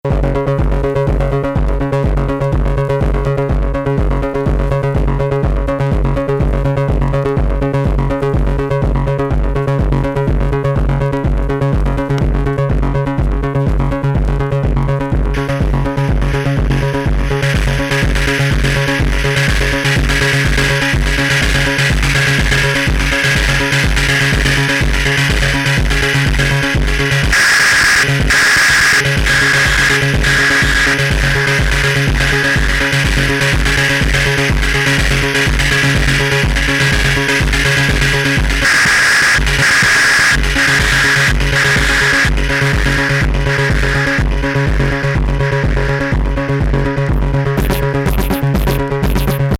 サウンドからこれ程バイオレンスなイメージを思い浮かばせる音楽もなかなか